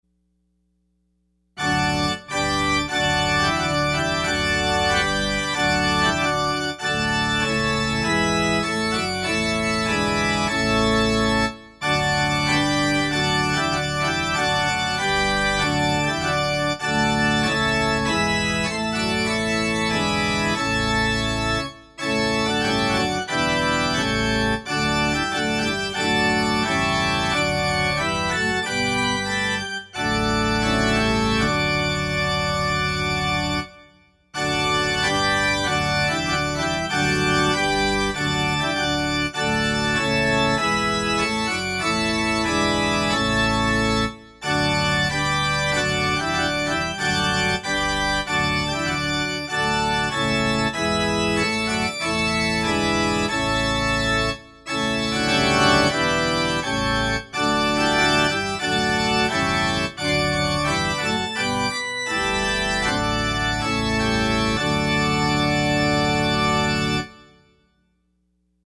Closing Hymn – Savior, like a Shepherd lead us